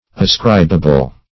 Ascribable \As*crib"a*ble\, a.